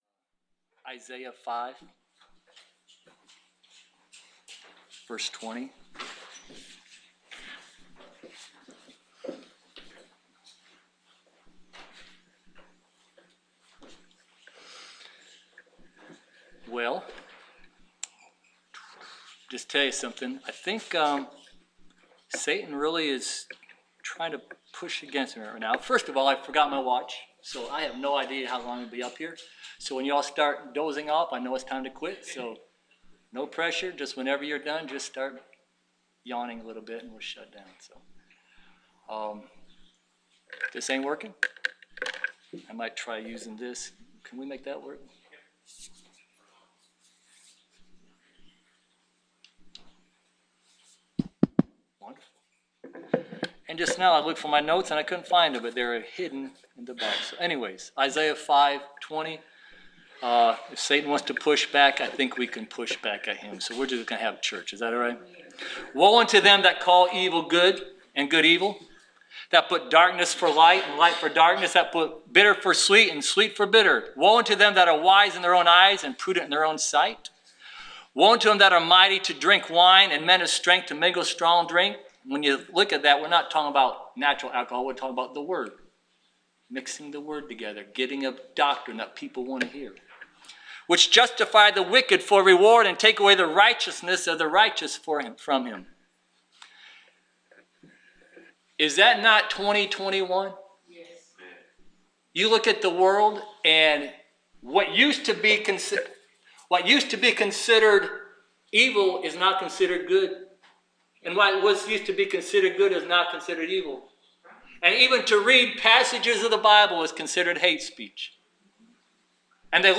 Preached July 25, 2021